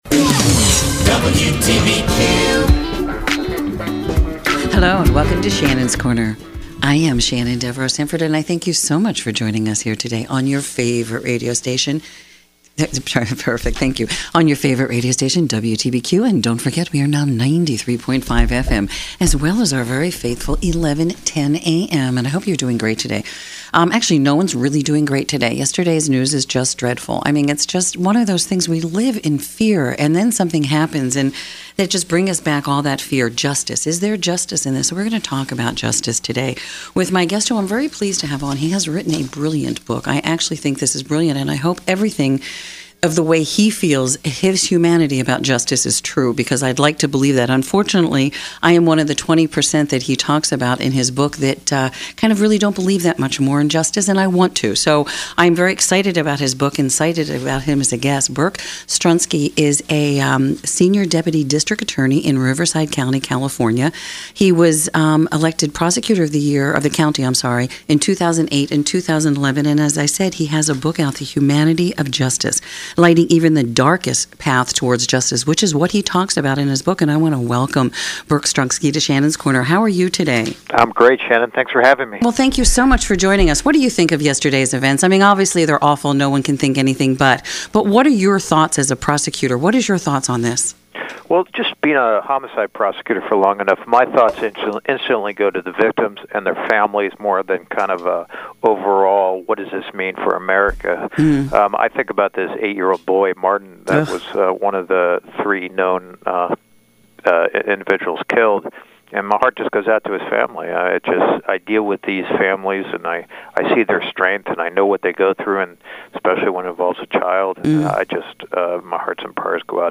Humanity of Justice Interview